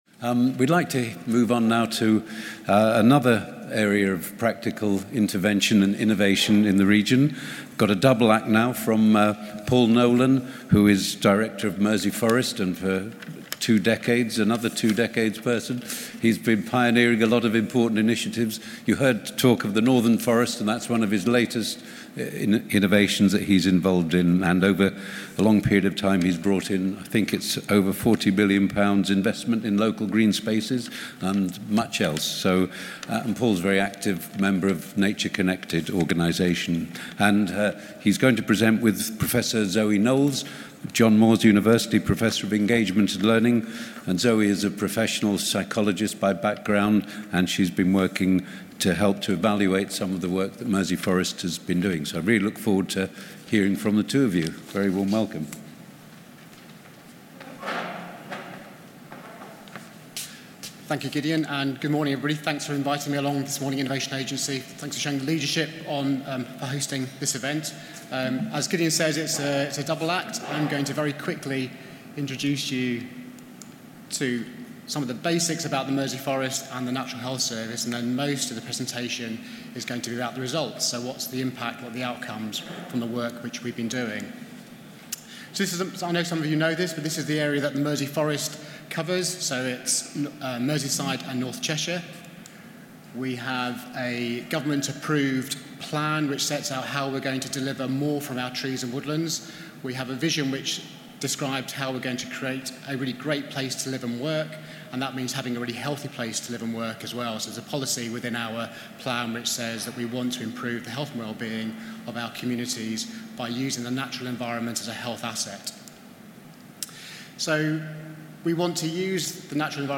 Recorded on 28 January 2019 during 'health, wellbeing and the environment' at The Isla Gladstone, Liverpool.